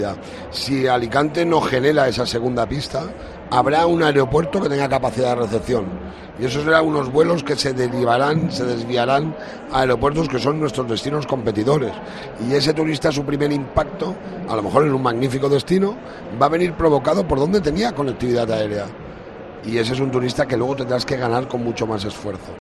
Toni Pérez, presidente de la Diputación: necesitamos la segunda pista del aeropuerto